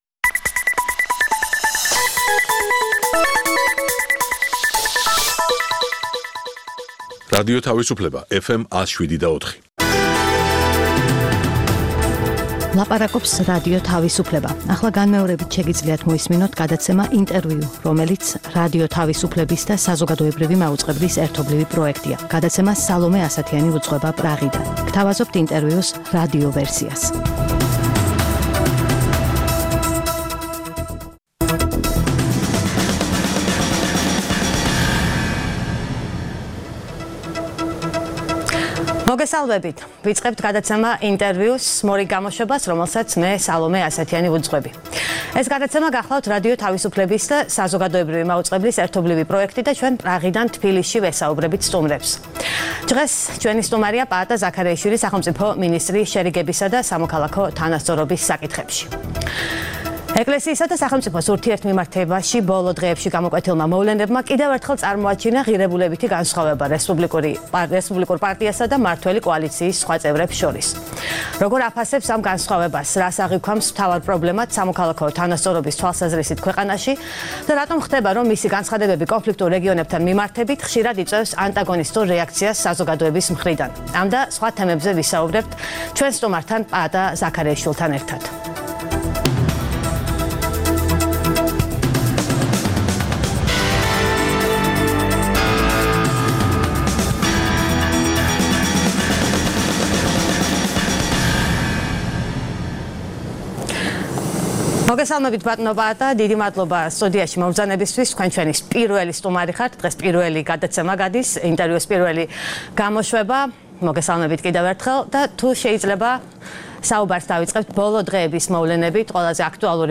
ყოველკვირეული გადაცემა „ინტერview“ არის რადიო თავისუფლებისა და საზოგადოებრივი მაუწყებლის ერთობლივი პროექტი. მასში მონაწილეობისთვის ვიწვევთ ყველას, ვინც გავლენას ახდენს საქართველოს პოლიტიკურ პროცესებზე. „ინტერview“ არის პრაღა-თბილისის ტელეხიდი
რადიო თავისუფლების პრაღის სტუდიიდან